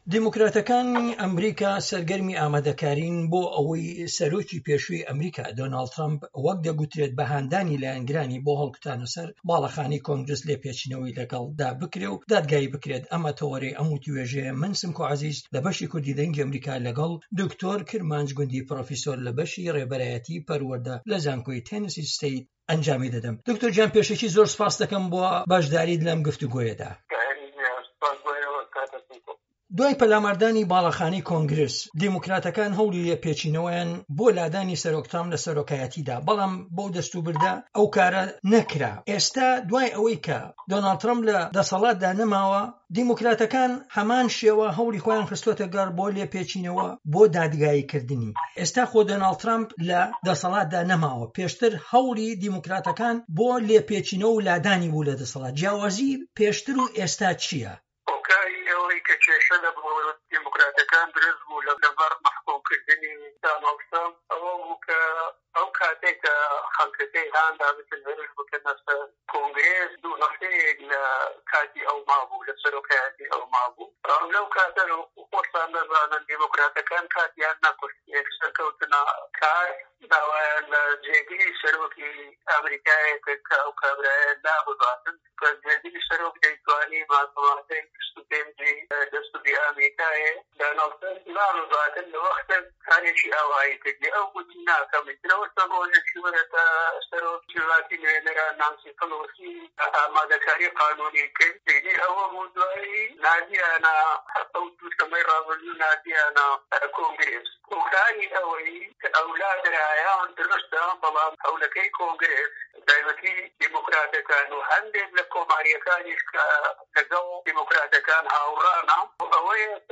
وتووێژەکان